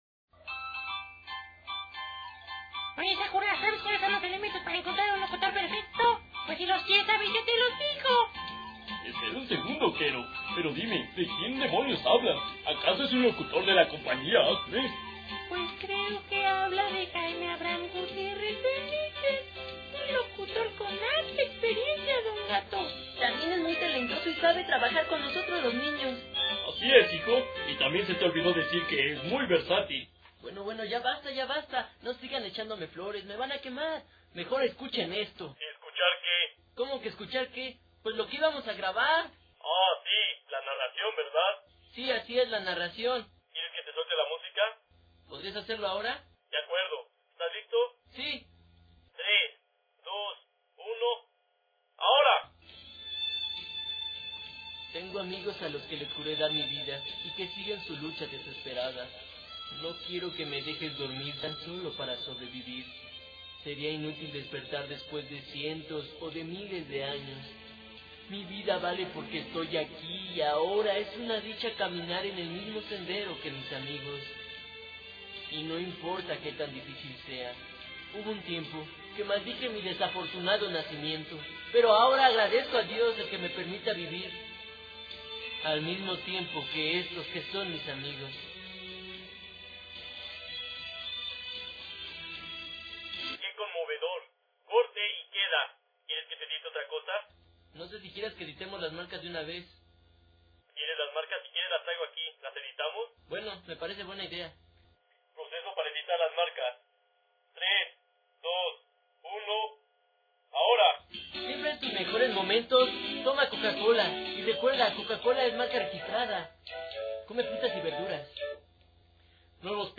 ACTOR EN DOBLAJE.
Estas son algunas de las voces que puedo realizar:
Cabe mencionar, que No soy Imitador, por lo que tengo un estilo muy pero muy propio.